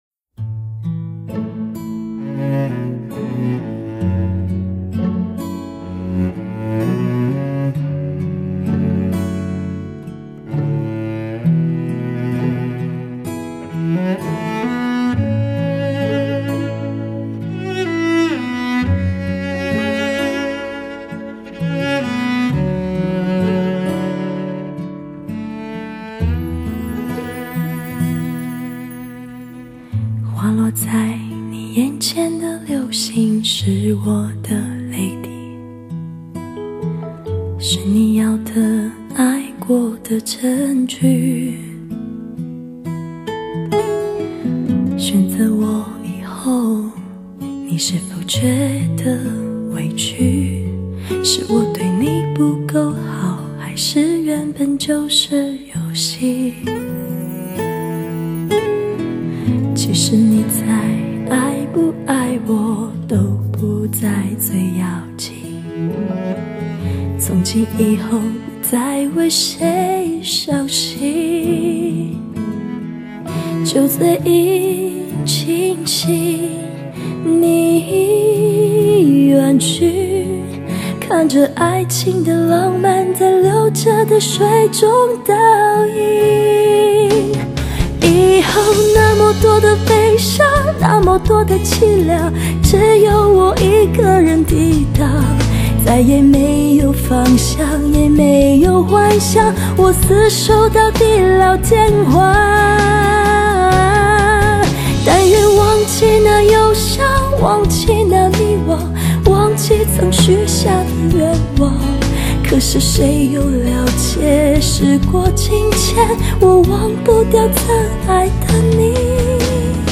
以完美颗粒的音乐和全无漏接的音效采集技术，空灵飘渺。